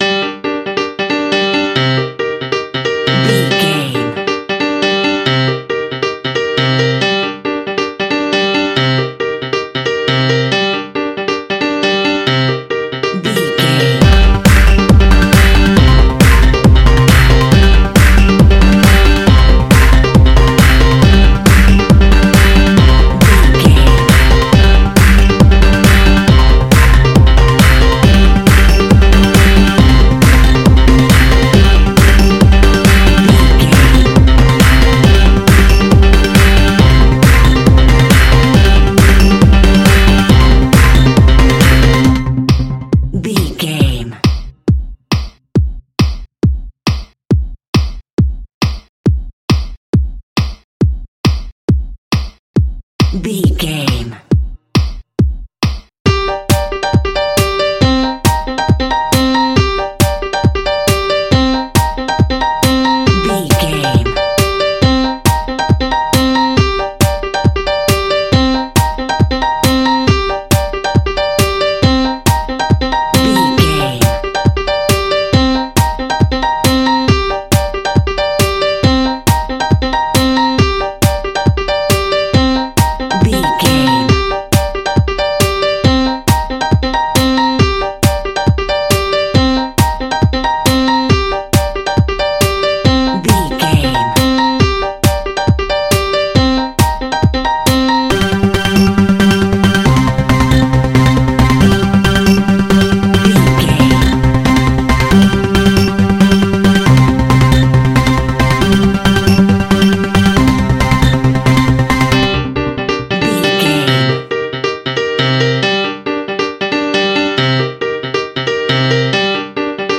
Aeolian/Minor
Fast
futuristic
energetic
uplifting
hypnotic
drum machine
piano
synthesiser
acid house
electronic
uptempo
synth leads
synth bass